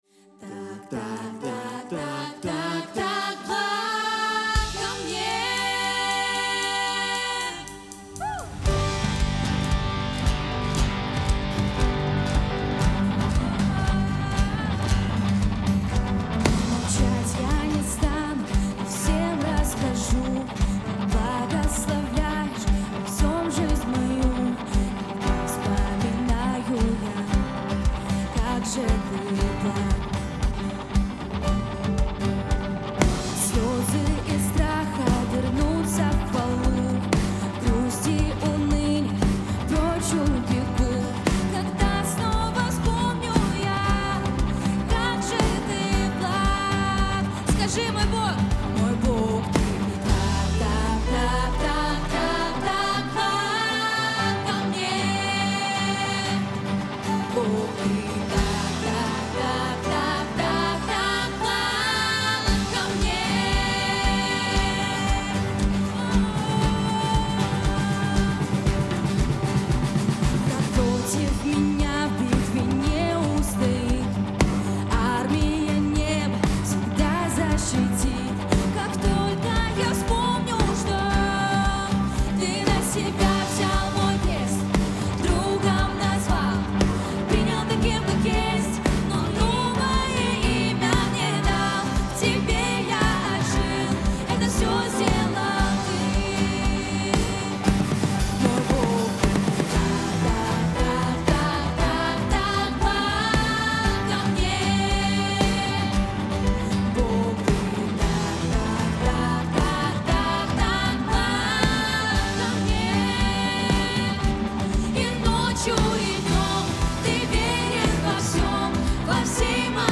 656 просмотров 762 прослушивания 15 скачиваний BPM: 116